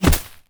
bullet_impact_grass_05.wav